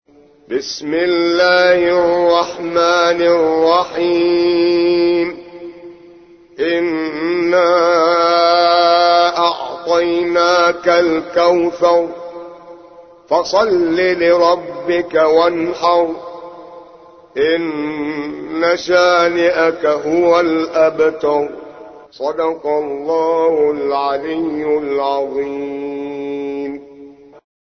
108. سورة الكوثر / القارئ